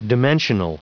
Prononciation du mot dimensional en anglais (fichier audio)
Prononciation du mot : dimensional